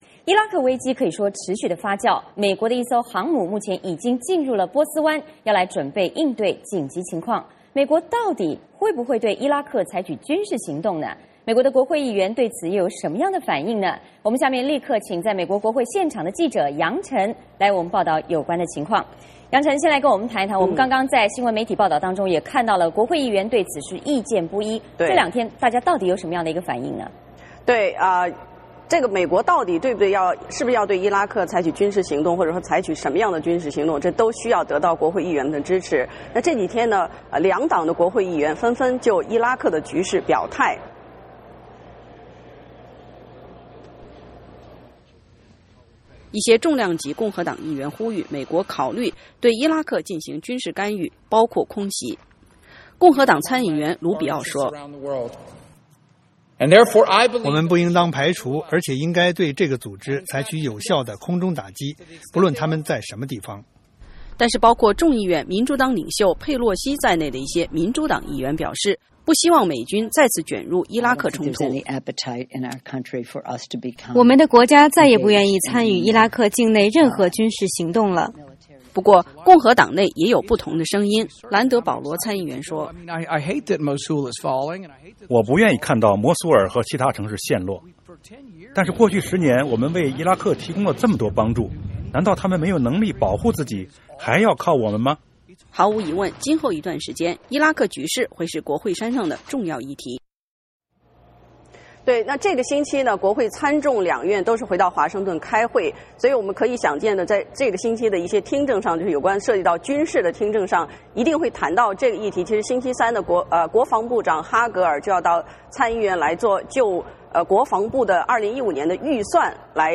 VOA连线：伊拉克危机升级，美国会议员纷表态